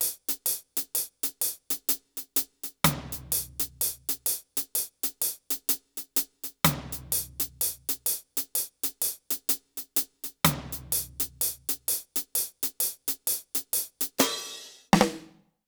British REGGAE Loop 132BPM (NO KICK) - 2.wav